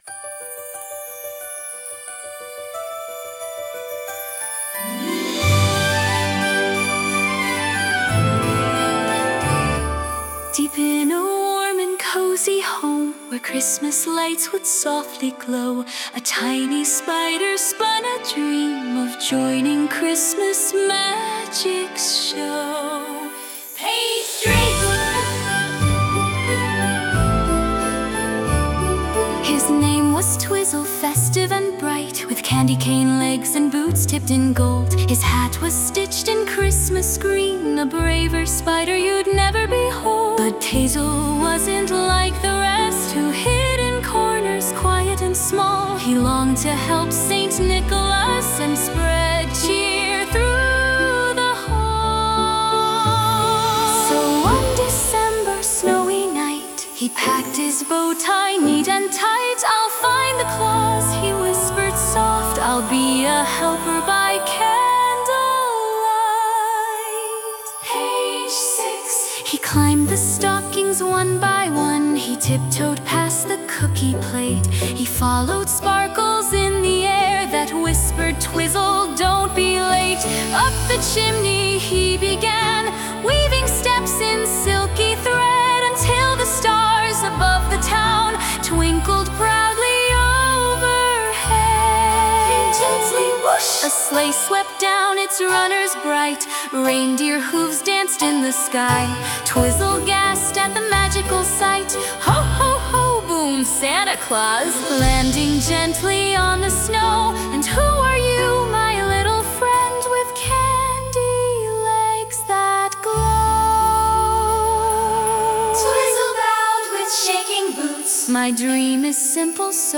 THE CHRISTMAS SPIDER AUDIO BOOK// STORY BOOK PRINT READY